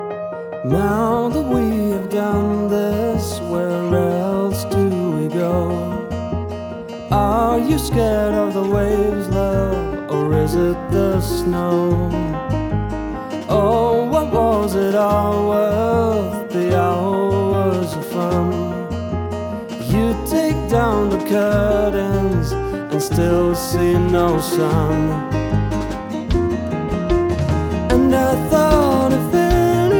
indie rock
bass, guitar and other instruments